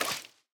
sounds / step / wet_grass5.ogg
wet_grass5.ogg